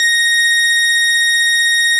snes_synth_082.wav